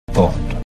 But in the new film, where 007 doesn’t deaccent his repeated surname, James no longer gets the main accent and no longer sounds like the end of a phrase:
We only feel we’ve heard a full phrase when we get to Bond: